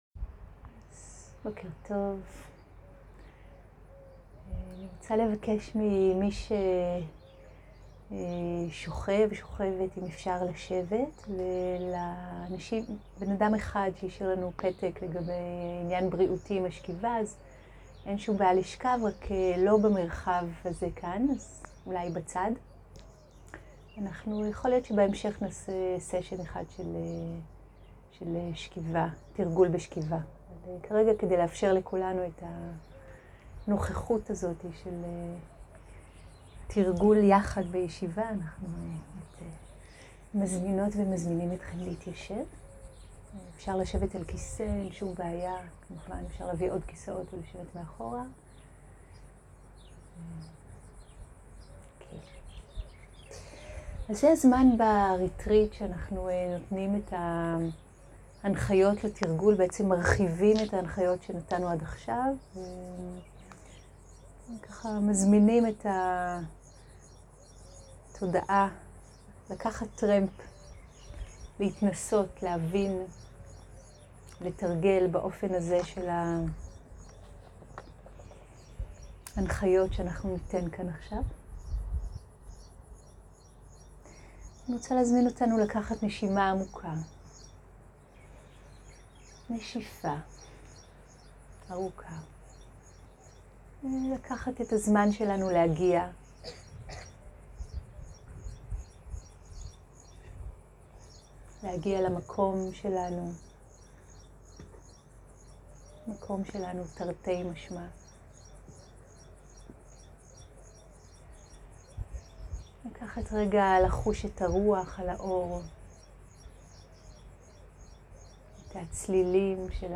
הנחיות לתרגול מדיטציה באויר הפתוח. נשימה והליכה בסוף
סוג ההקלטה: שיחת הנחיות למדיטציה